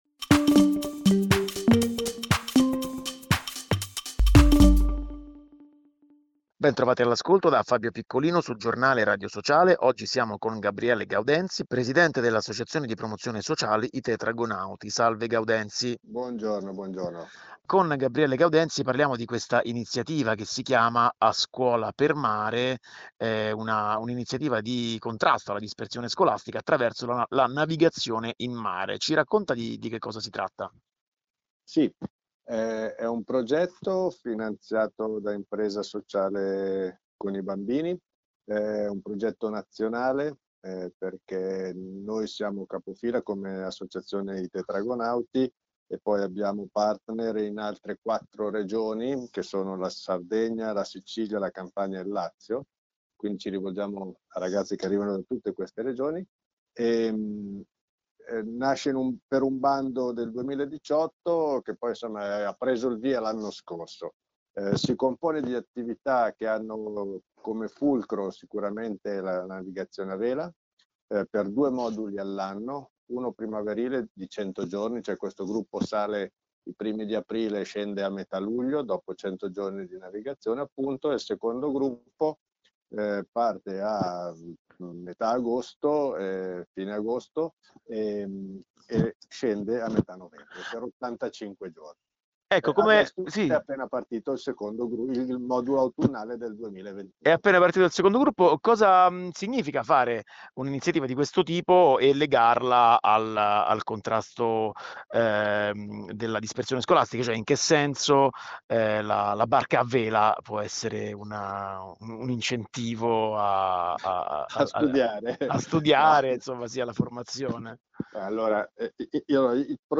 In mare contro la dispersione scolastica. Intervista